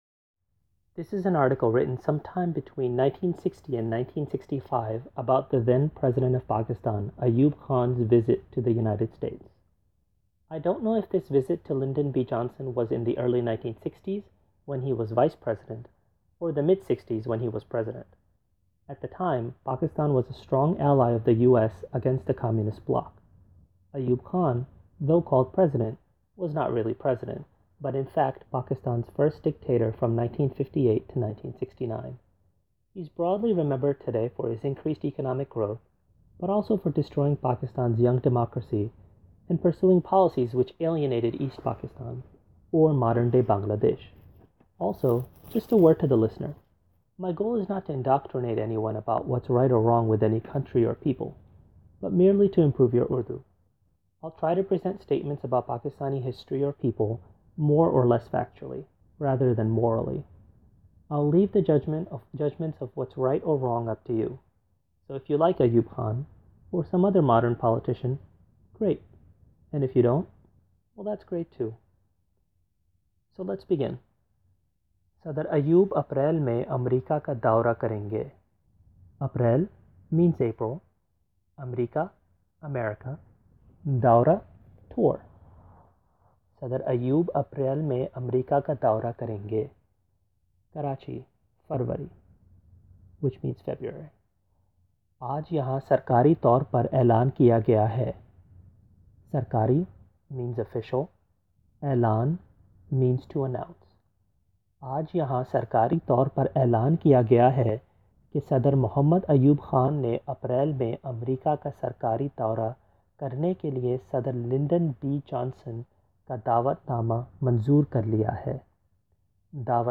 The first audio recording will translate the difficult words in English as I read along in Urdu.